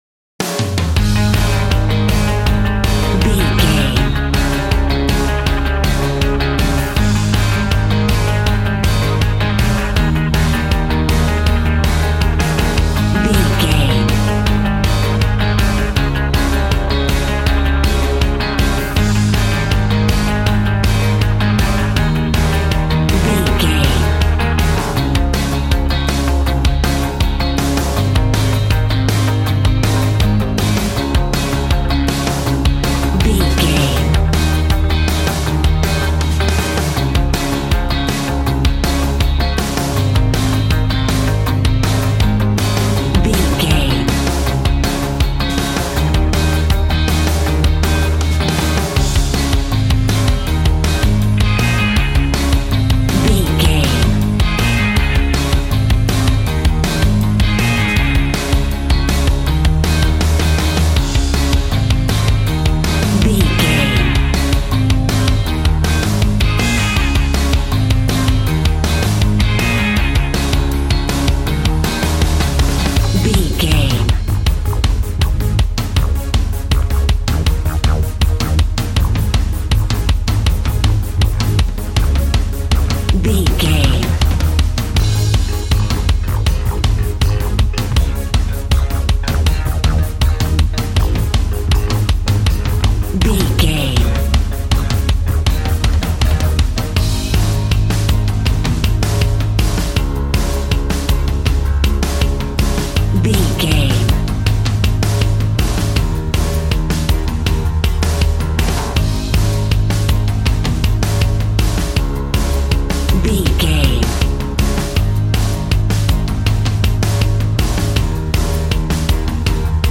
Ionian/Major
A♭
groovy
fun
happy
electric guitar
bass guitar
drums
piano
organ